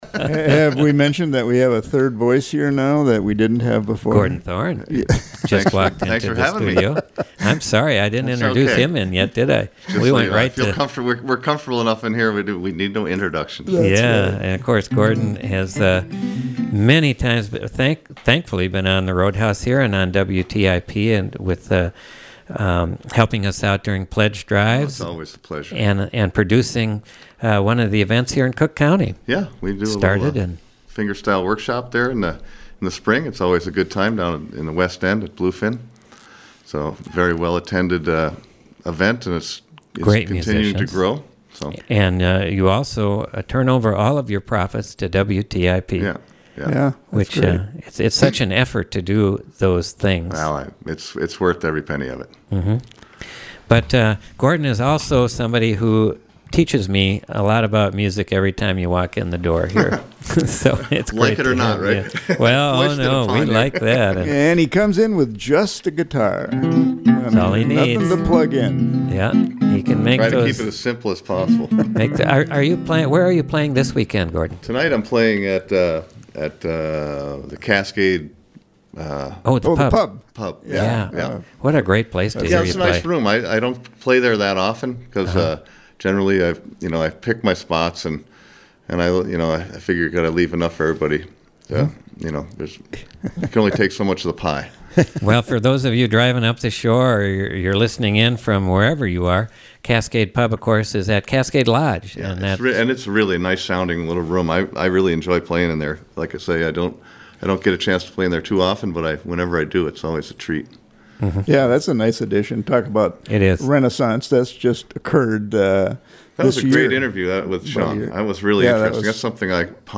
Fingerstyle guitarist